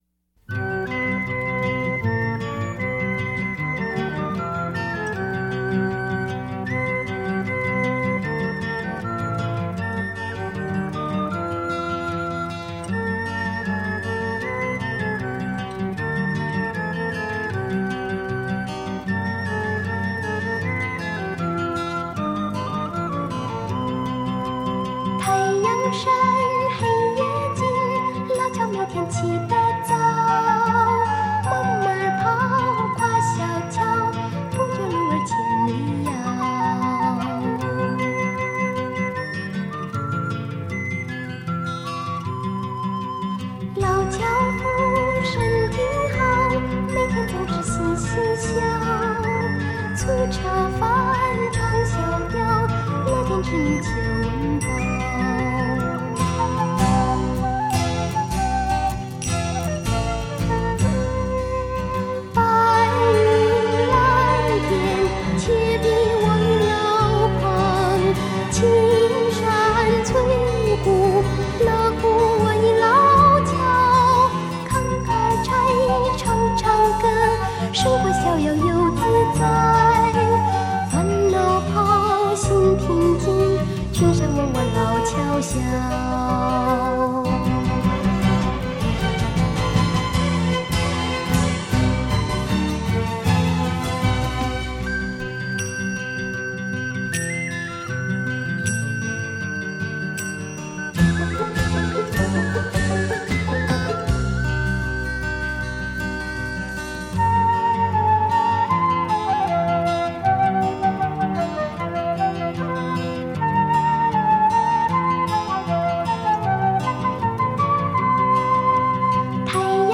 民歌界的歌唱女精灵音色清亮而带童趣独特嗓音见长广受听众喜爱歌伶